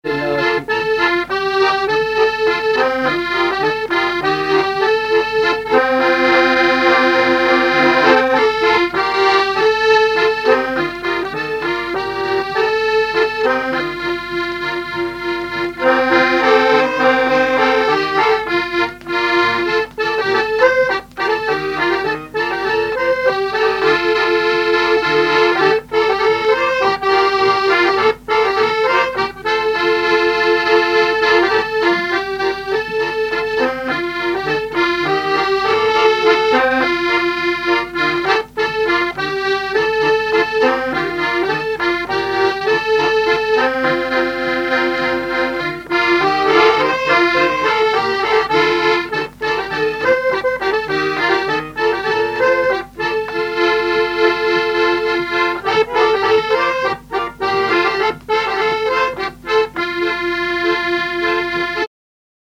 Fonction d'après l'informateur gestuel : à marcher
Genre laisse
Pièce musicale inédite